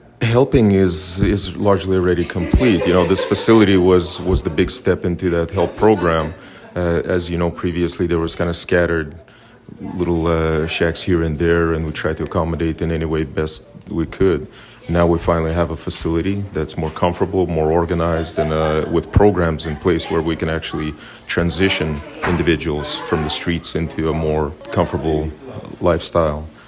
Lac La Biche County Mayor Paul Reutov says that the facility is a large step towards the help program within the community, adding that the newly built Transitional Housing Facility will aid in helping the houseless community from the streets to a more comfortable lifestyle.